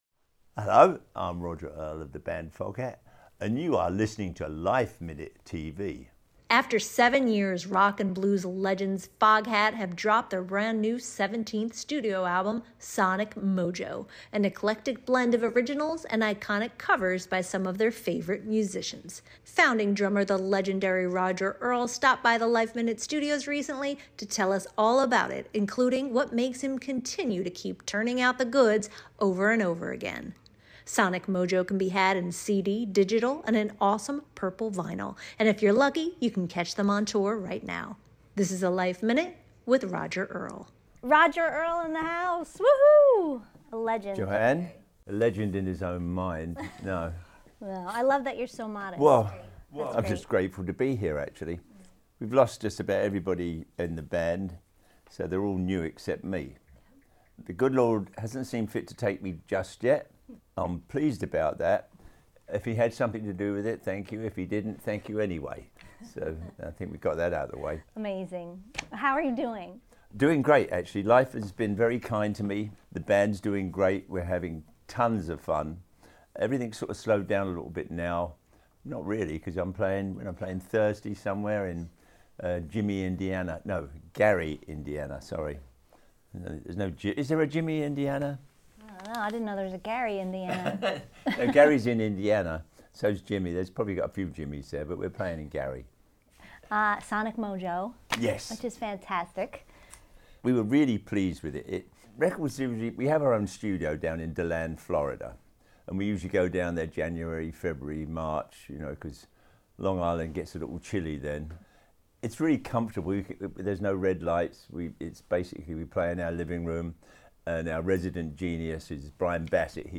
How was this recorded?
stopped by the LifeMinute Studios to tell us all about it